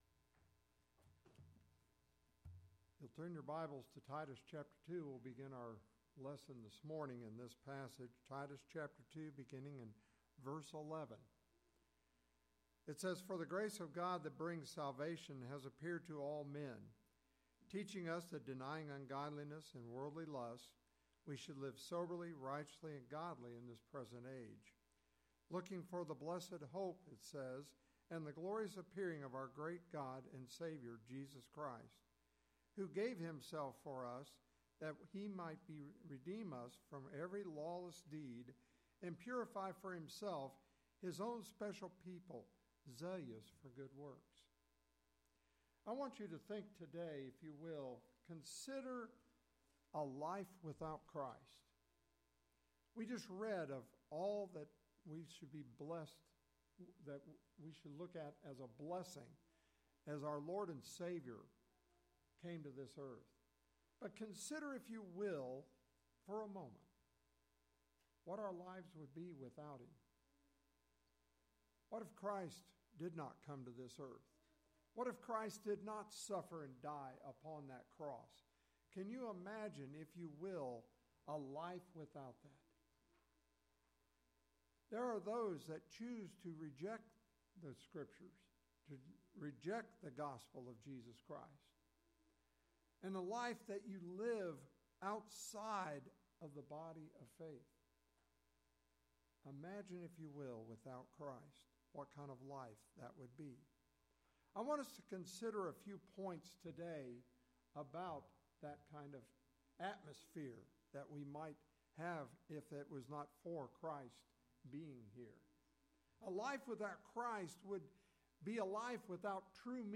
The sermon offers a clear and compelling message about the necessity of Christ.